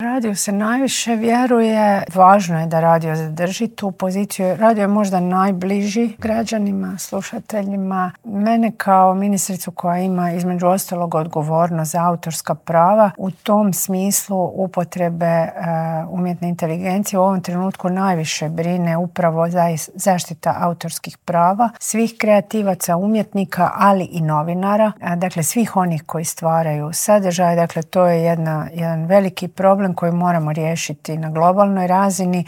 Tema ovogodišnjeg Svjetskog dana je Radio i Umjetna inteligencija. Iako ga se mnogo puta otpisivalo, radio je opstao i zadržao povjerenje slušatelja, ističe ministrica kulture i medija Nina Obuljen Koržinek.